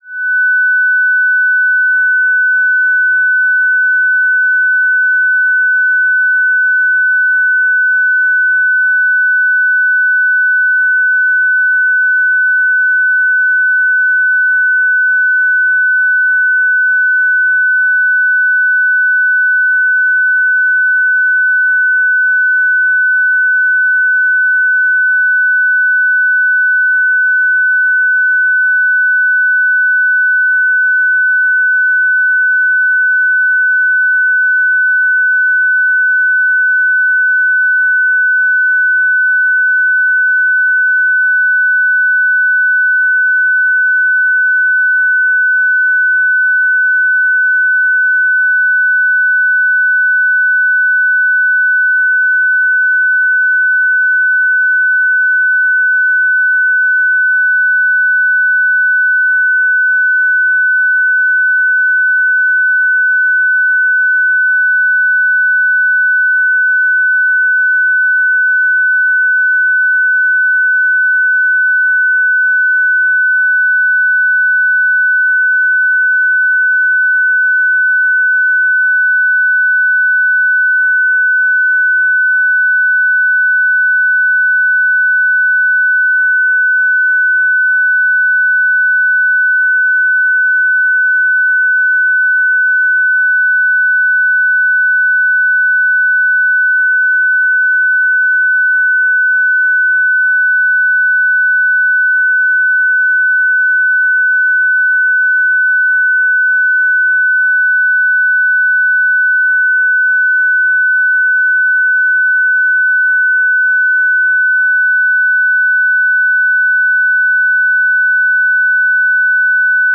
FST4W-300.ogg